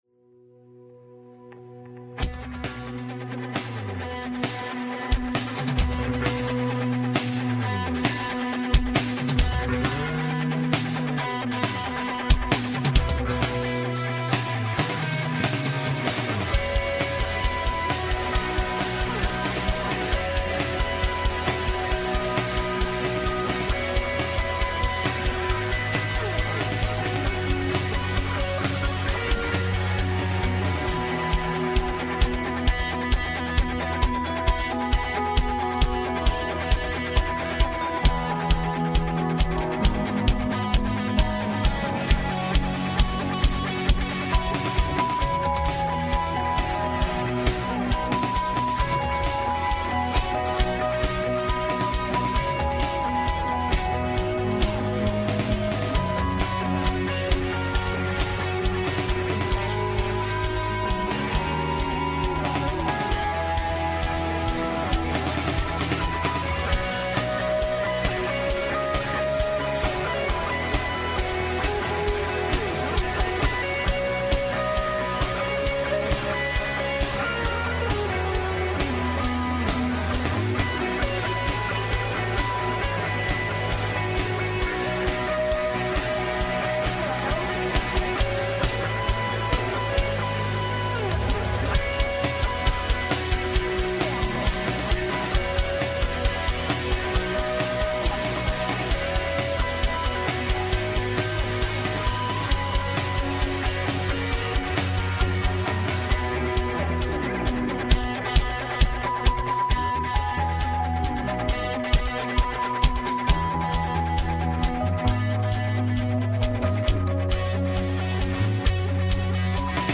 Westgate Chapel Sermons Mother's Day 2025 May 11 2025 | 01:26:40 1x 00:00 / 01:26:40 Subscribe Share Apple Podcasts Overcast RSS Feed Share Link Embed